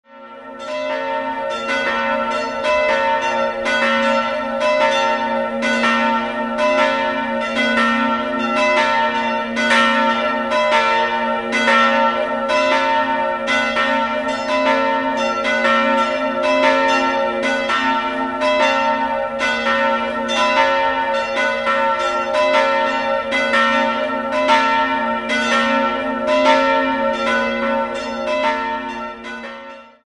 Beschreibung der Glocken
Im Inneren trifft man auf eine barocke Ausstattung mit drei schönen Altären. 3-stimmiges Geläute: a'-h'-e'' Eine genaue Glockenbeschreibung folgt unten.